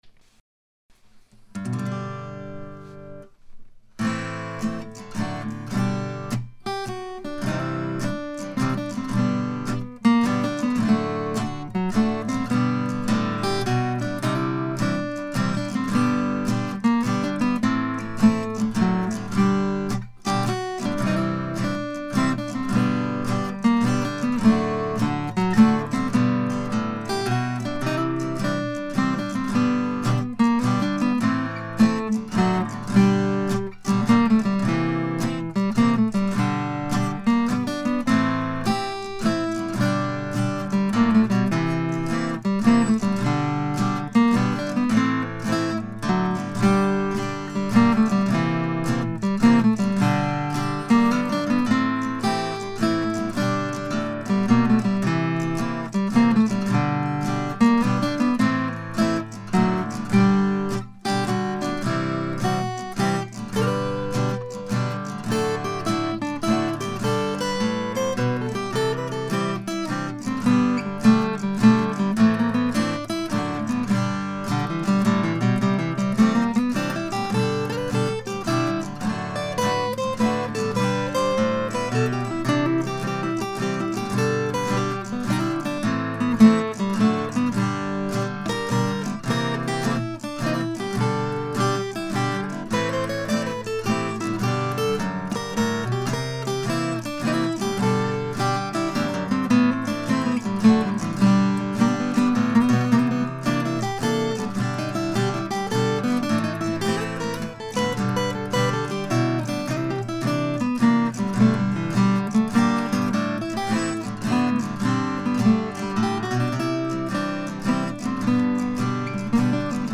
The tune itself, a country kind of waltz with a little blues in the B section, was recorded last weekend. I only used guitar on this one and I indulged in a little improvisation the second time through the tune. You can hear that I was enjoying myself so much that I forgot to smoothly segue from improv to melody when going back to the head.